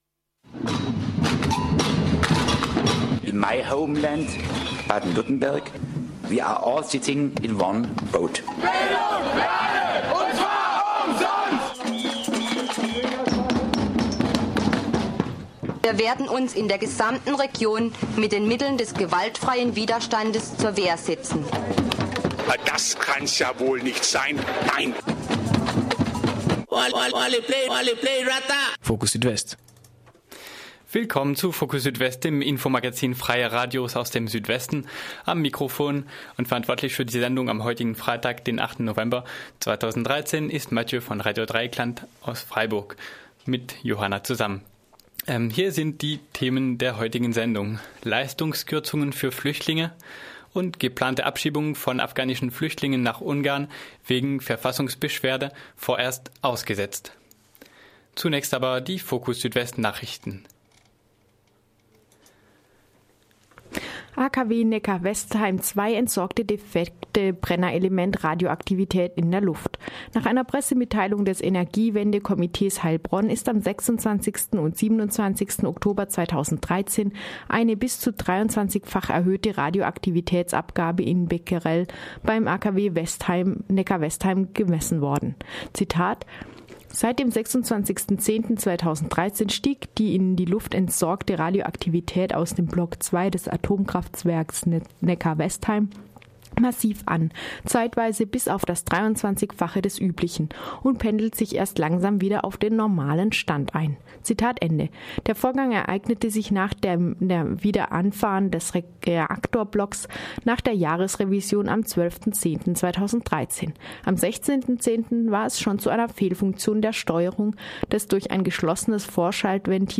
Nachrichten: